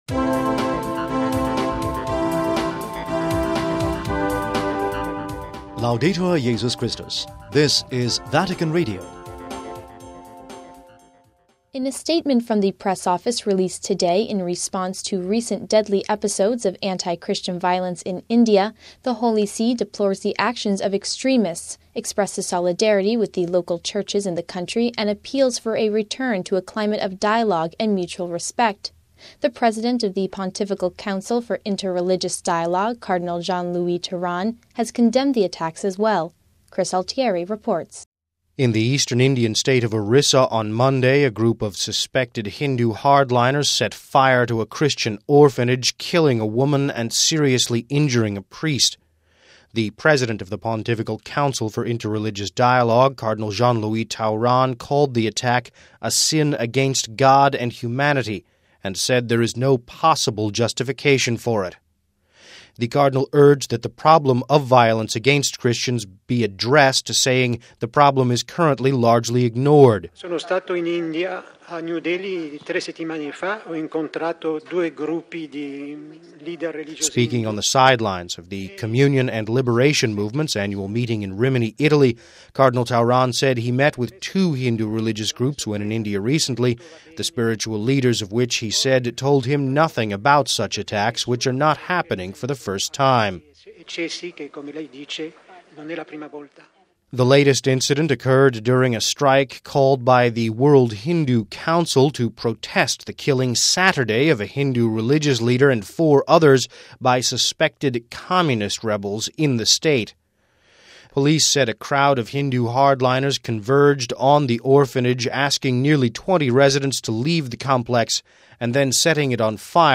We have this report...